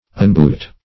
Unboot \Un*boot"\